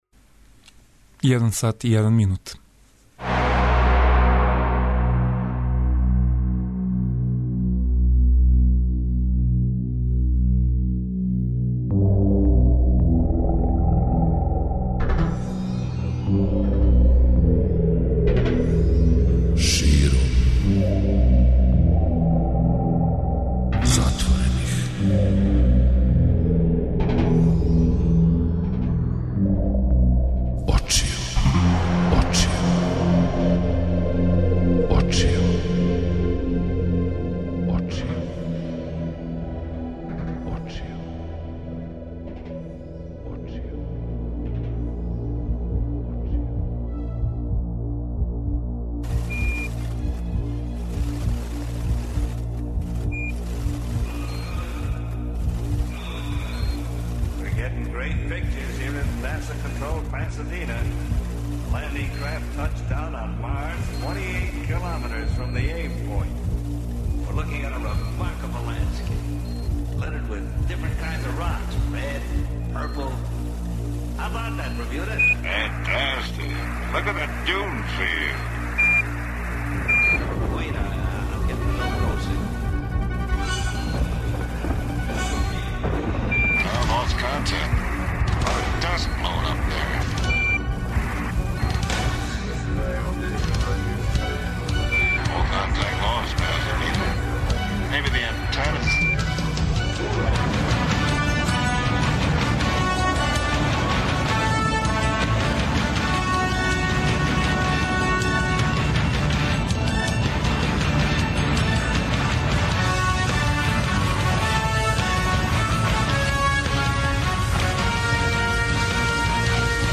Очекују вас и стандардне рубрике - "СФ времеплов", "Инспирација" и за сам крај "Трећа страна плоче" - у којој слушамо једну од нумера из филма којег ускоро гледамо, и то у а капела верзији!